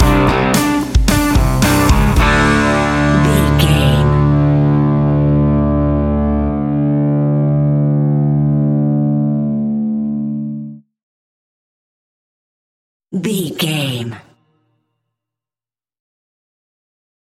Ionian/Major
D
energetic
driving
aggressive
electric guitar
bass guitar
drums
hard rock
heavy metal
distorted guitars
hammond organ